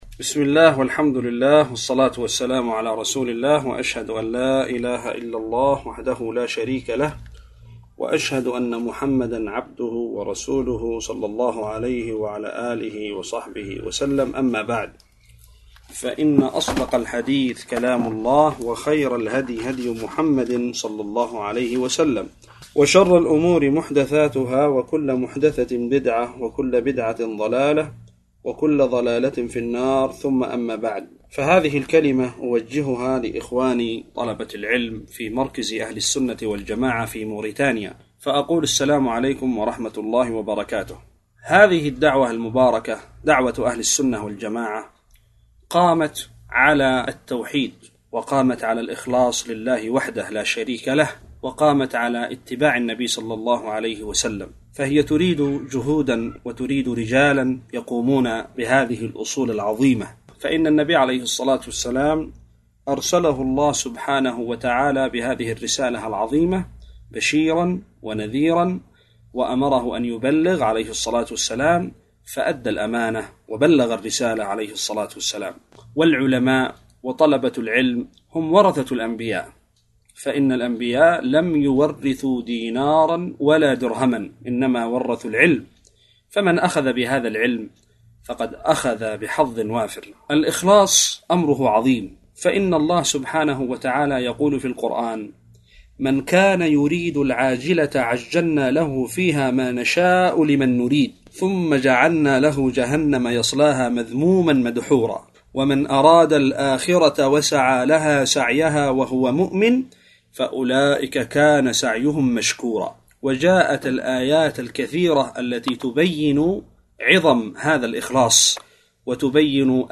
كلمة توجيهية للإخوة في مركز أهل السنة والجماعة في موريتانيا